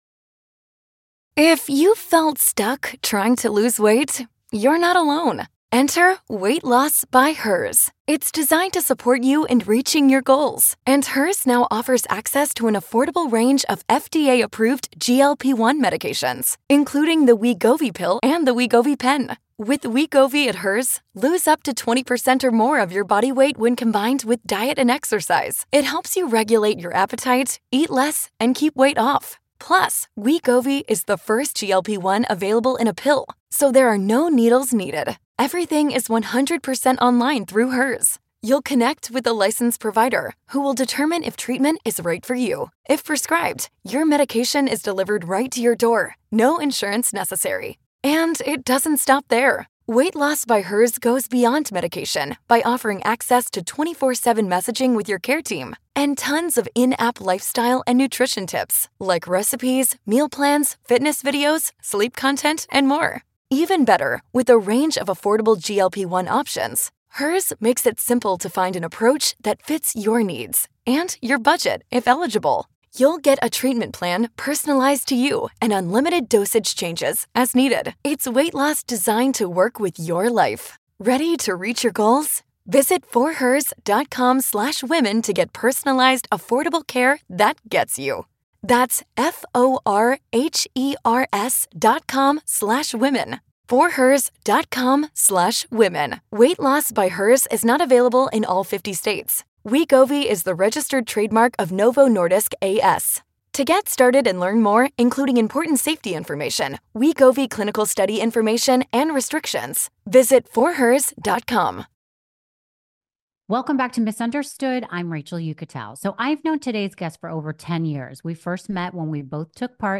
Jason opens up about growing up on TV, his struggles with addiction and what led him down the road of recovery. Rachel and Jason share stories from their time on season 4 of Celebrity Rehab with Dr. Drew, the laughs they had and the deep bonds they formed with their castmates. Jason gets honest about his family, his passion for working in the addiction space and what he does to stay sober and happy. Enjoy this intimate conversation between friends!